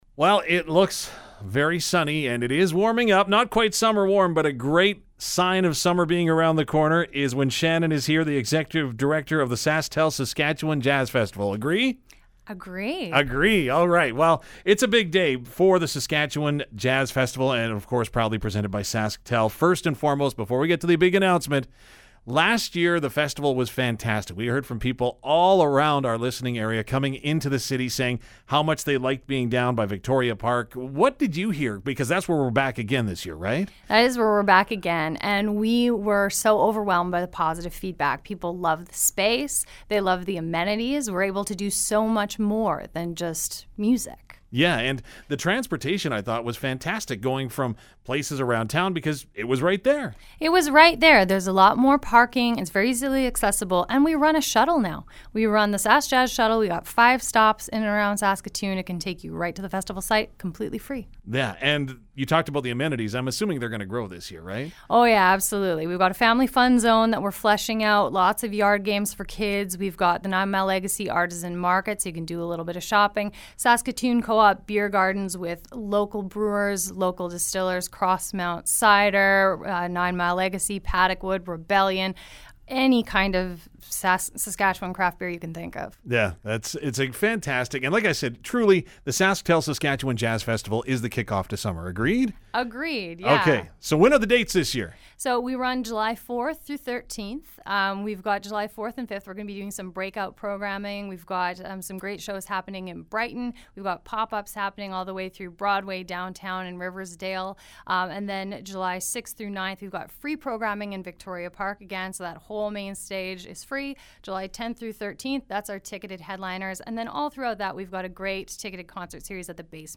sk-jazz-festival-lineup-announcement.mp3